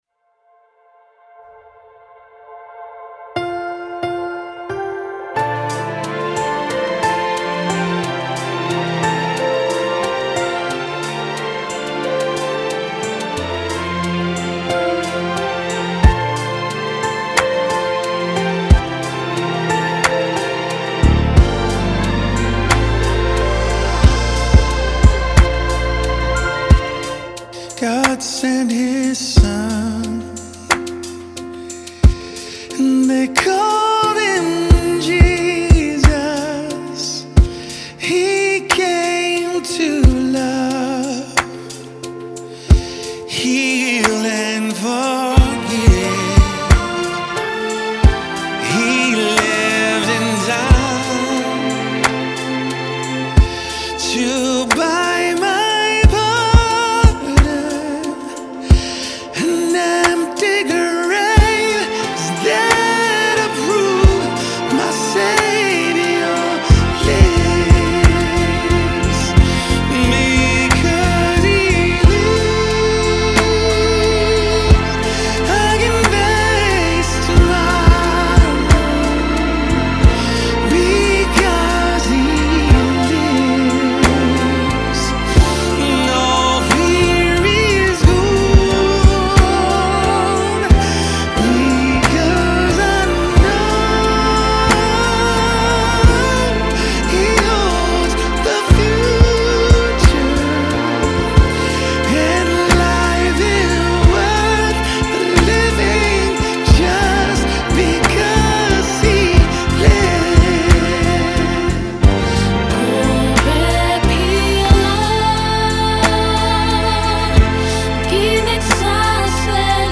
Virtual Choir for Easter
During the worship service, our worship team will sing a special version of “Because He Lives” that includes four different languages from all over the world: English, Zulu, Mandarin Chinese, and Spanish.
When you listen to the track, you’ll hear the four different languages as follows: